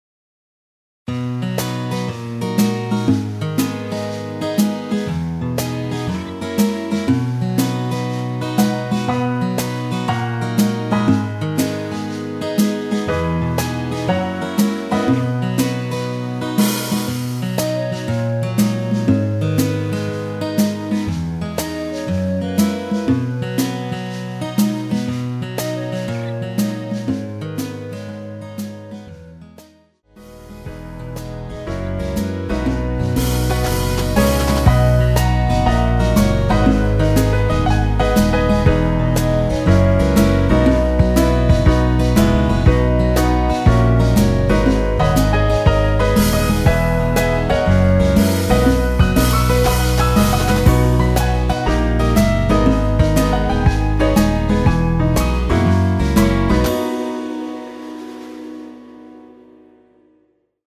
엔딩이 페이드 아웃에 너무 길어서 4마디로 엔딩을 만들었습니다.(미리듣기 참조)
원키에서(-1)내린 멜로디 포함된 MR 입니다.
앞부분30초, 뒷부분30초씩 편집해서 올려 드리고 있습니다.